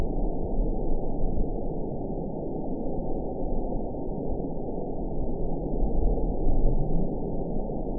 event 910606 date 01/22/22 time 23:05:50 GMT (3 years, 4 months ago) score 9.32 location TSS-AB04 detected by nrw target species NRW annotations +NRW Spectrogram: Frequency (kHz) vs. Time (s) audio not available .wav